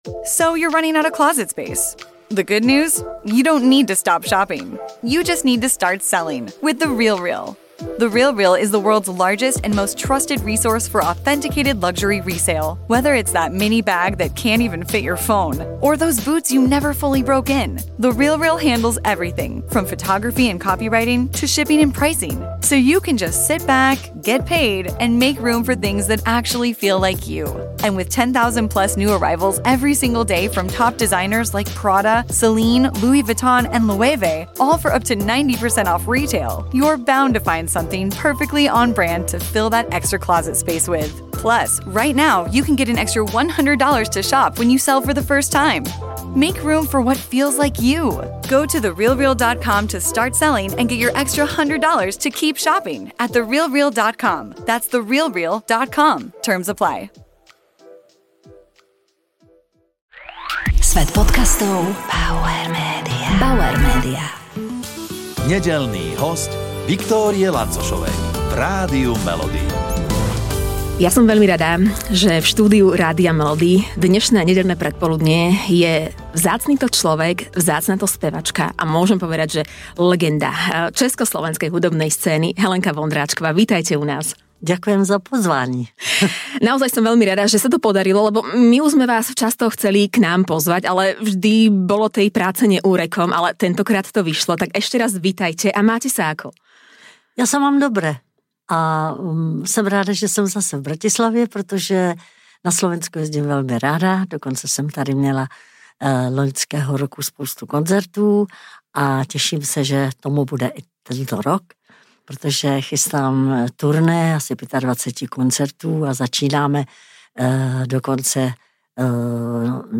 V rozhovore porozprávala o filme Šviháci aj o spolupráci so zatiaľ jej najmladším kolegom. Prezradila, či si vie vychutnať masáže a kúpele, akým športom sa stále venuje, a tiež to, čo ju motivuje neustále vystupovať pred publikom a koncertovať.